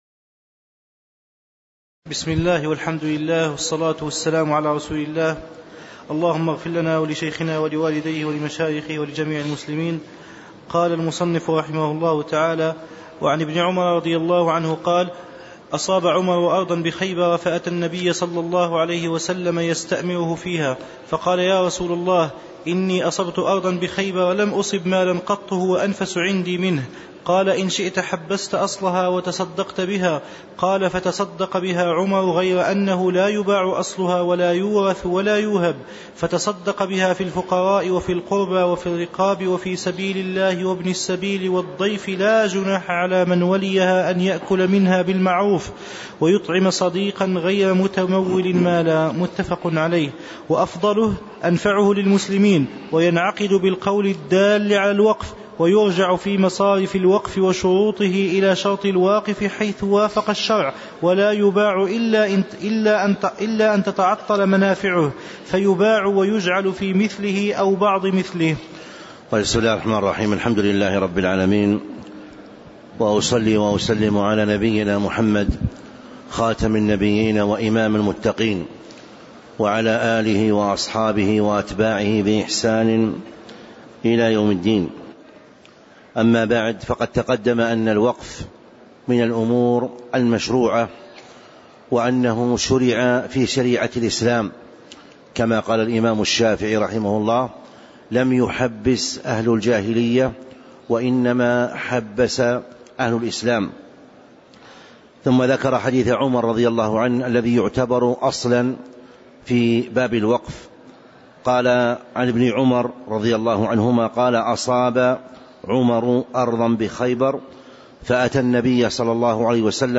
تاريخ النشر ٢٩ ربيع الأول ١٤٤٦ هـ المكان: المسجد النبوي الشيخ